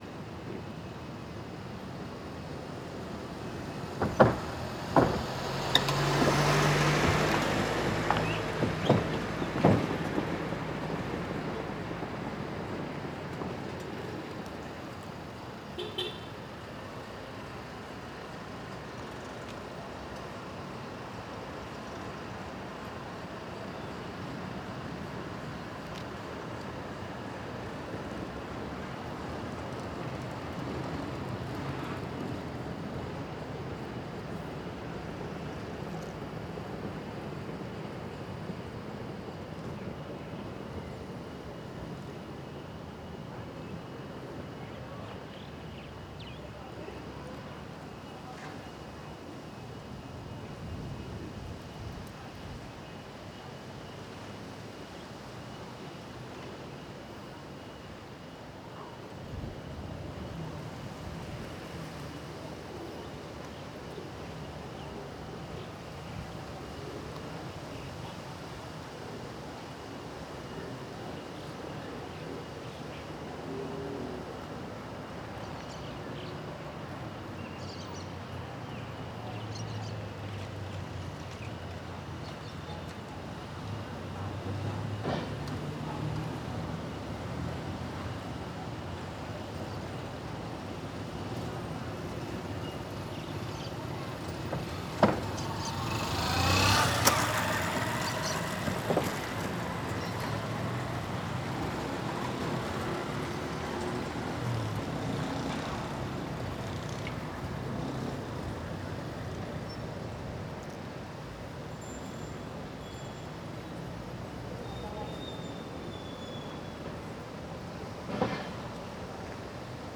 Arquivo de Vento - Coleção Sonora do Cerrado
CSC-18-081-LE - Ponte cora coralina transito ponte de madeira rua de pedra, passaros, pessoas e vento forte no meio.wav